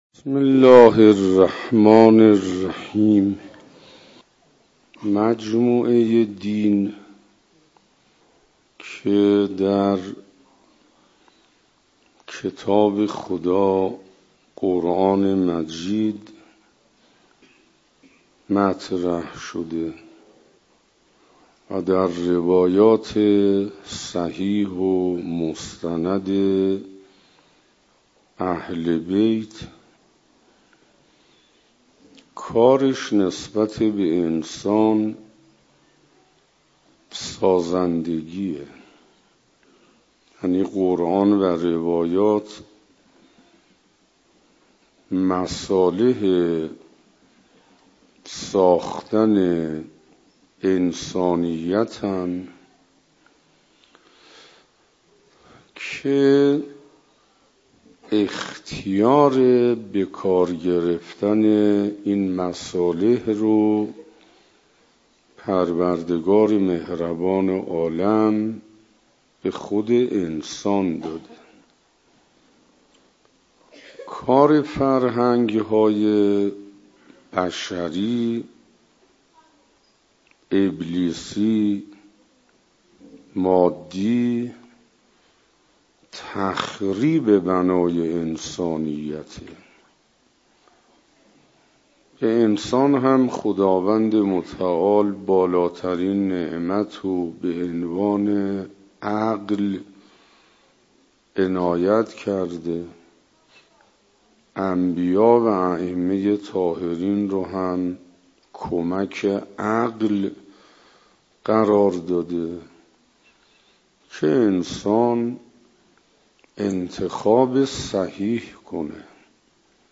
در این بخش از ضیاءالصالحین، هفتمین جلسه از بیانات ارزشمند و معرفت افزای استاد حسین انصاریان را با موضوع «یاد خدا» به مدت 46 دقیقه تقدیم سالکان طریق الی الله می نمایم.